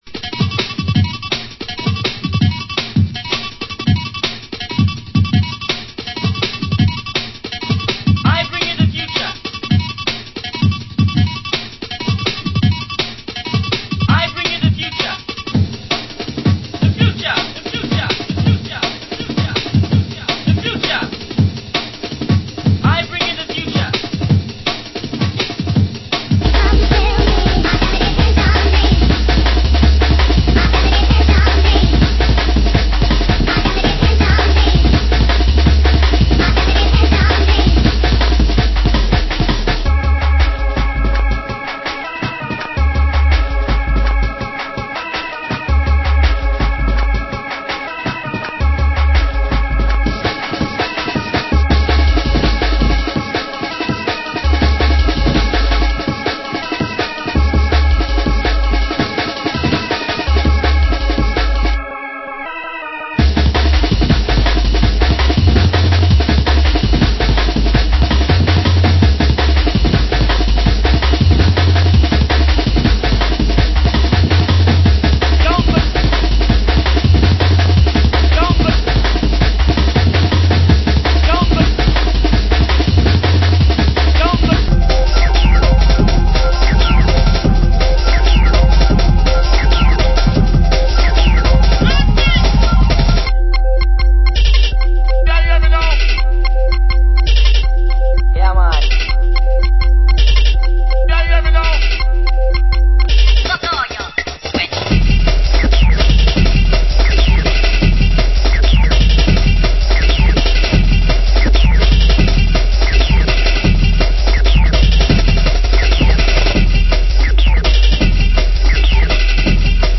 Genre Hardcore